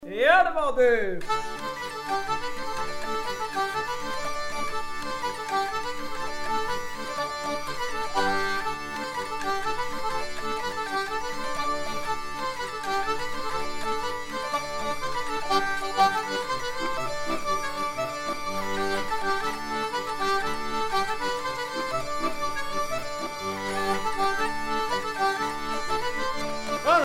danse : branle : avant-deux
Pièce musicale éditée